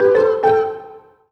happy_collect_item_07.wav